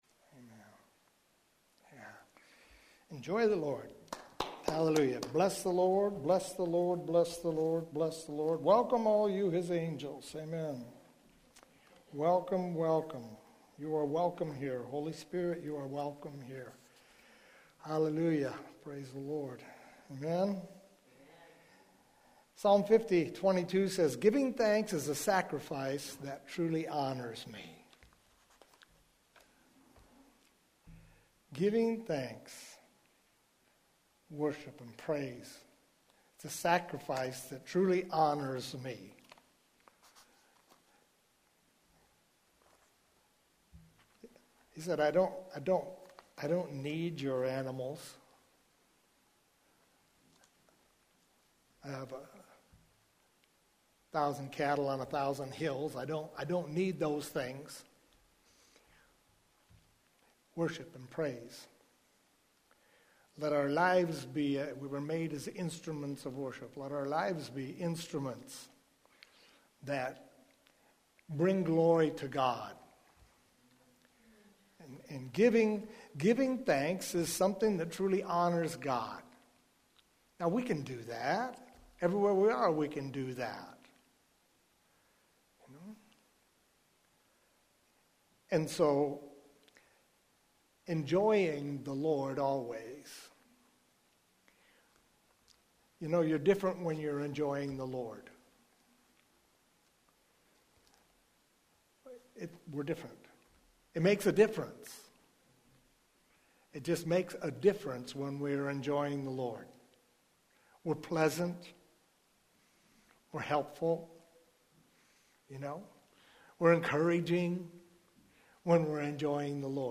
Here you’ll find a selection of audio recordings from Hosanna Restoration Church.
Longer sermons are broken up into smaller...